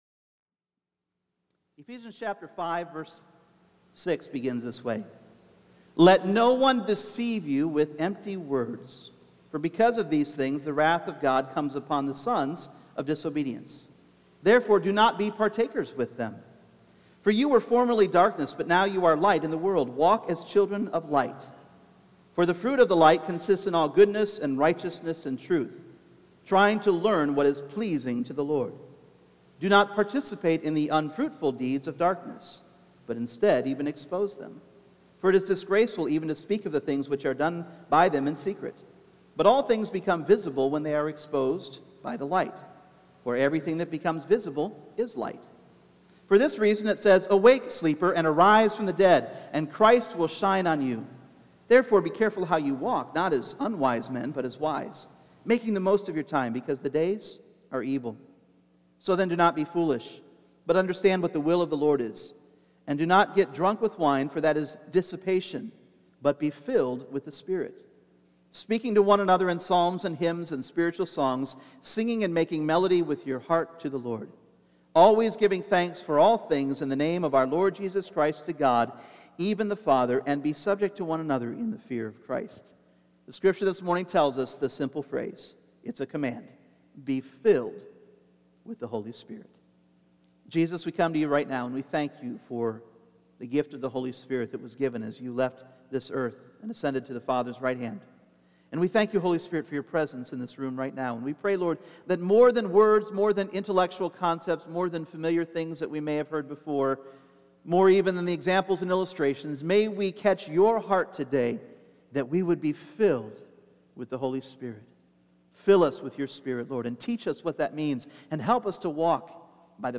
Individual Messages Service Type: Sunday Morning The power of the Holy Spirit at work in our lives is like no other substitute!